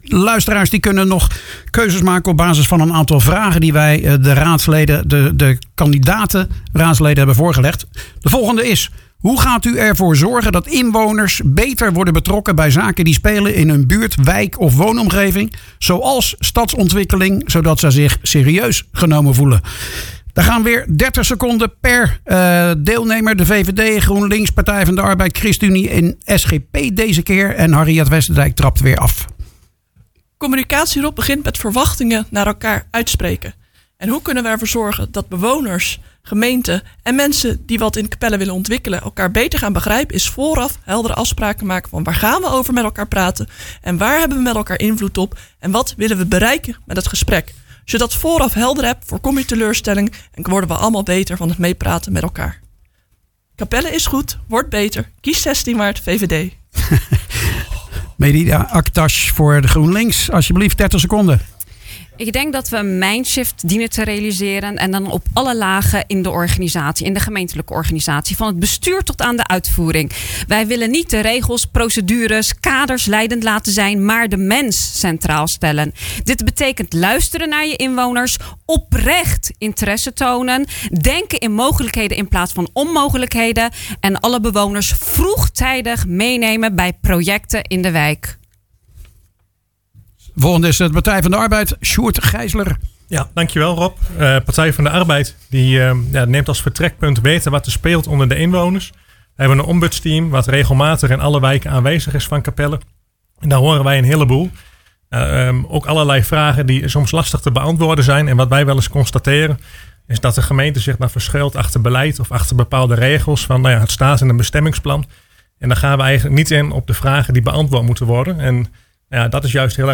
Op zaterdag 12 maart was in de studio van Radio Capelle het laatste verkiezingsdebat voordat de stembureaus opengaan.